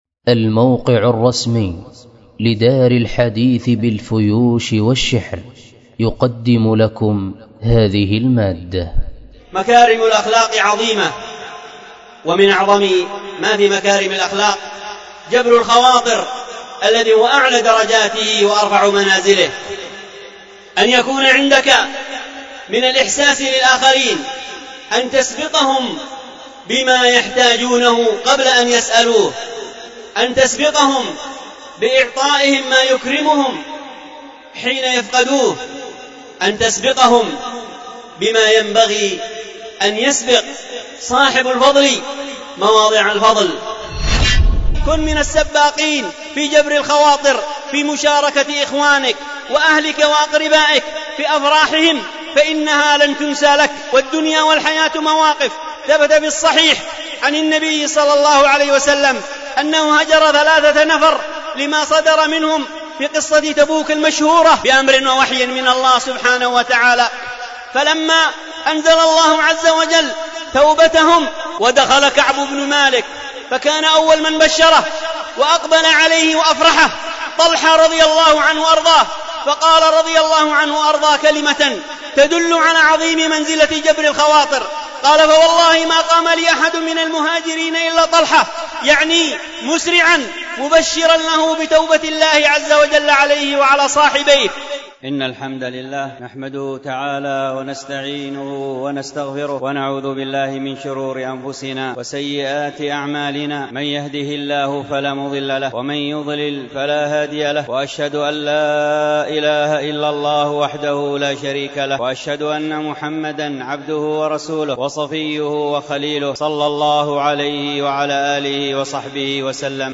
الدرس في التعليق على مقدمة سنن الدارمي 14، ألقاها